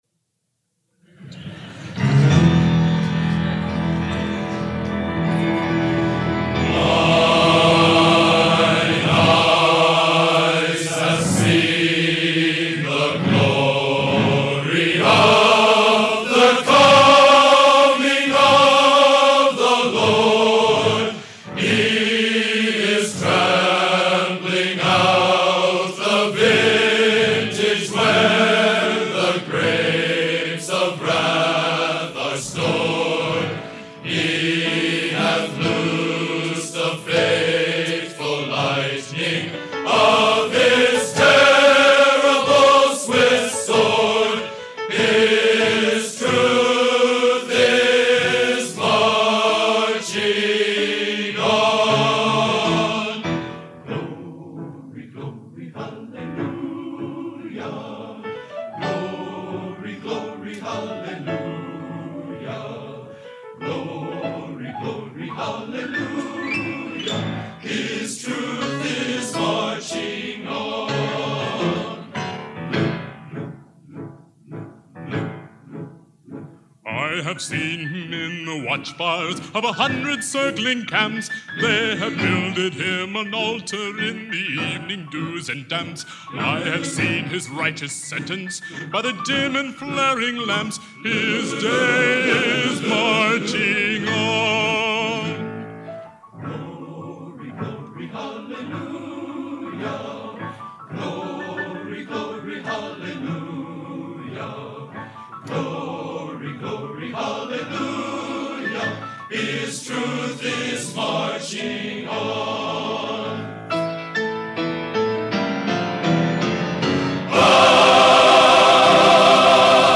Genre: Patriotic Traditional | Type: End of Season